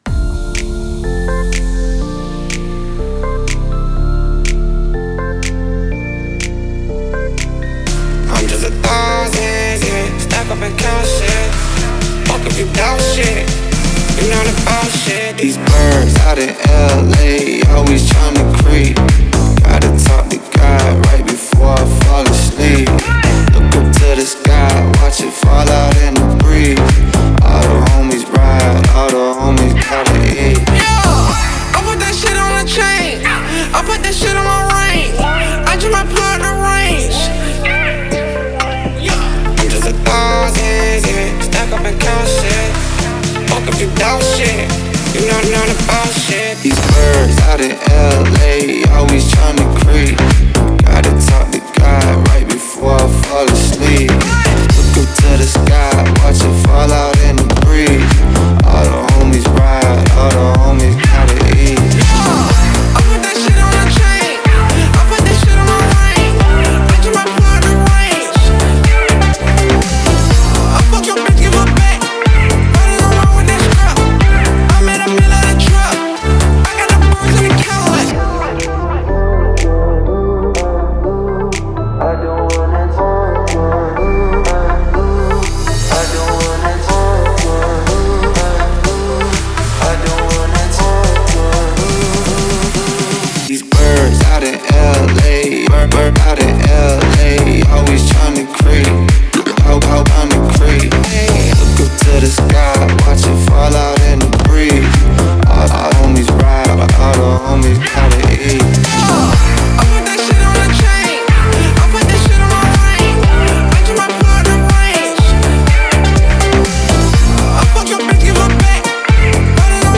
это энергичная трек в жанре хип-хоп